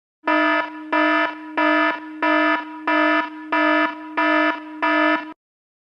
alerta.mp3